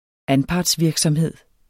Udtale [ ˈanpɑds- ]